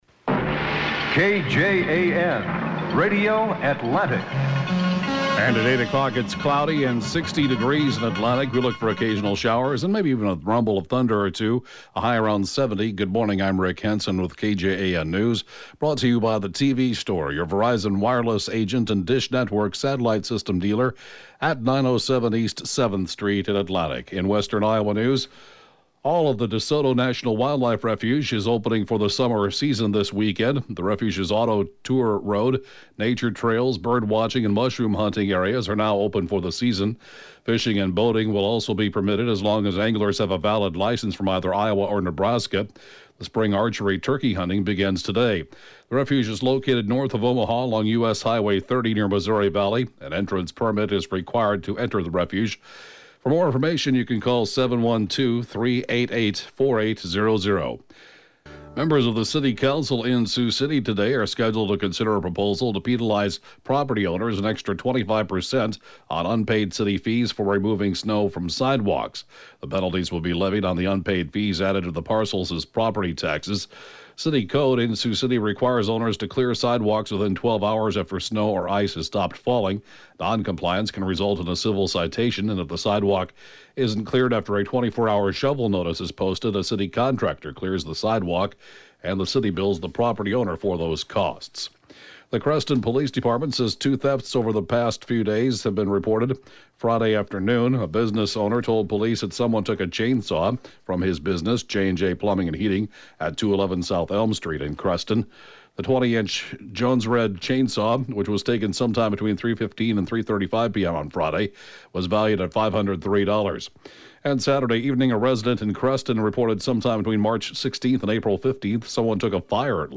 (Podcast) 7:07-a.m. News & funeral announcement, 6/13/2014